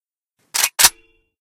ak74_misfire.ogg